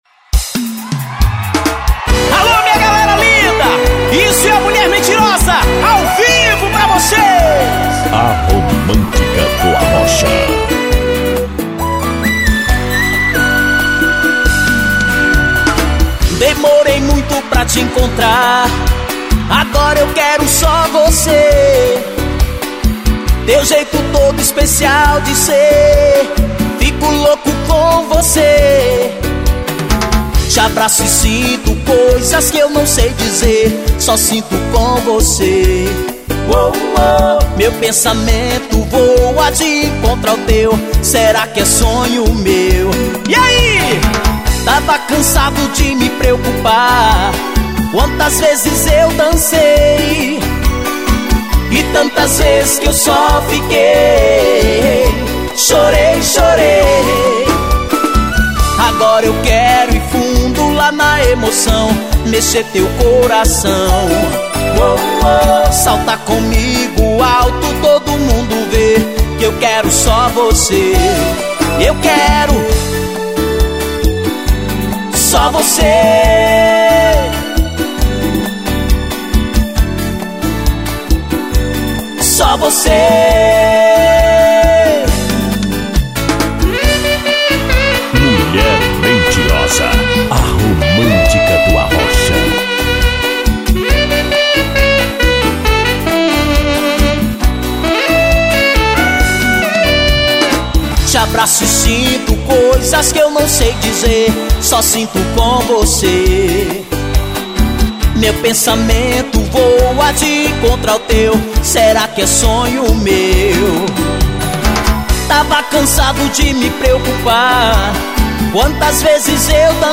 na pegada do arrocha